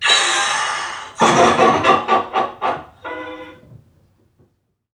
NPC_Creatures_Vocalisations_Robothead [52].wav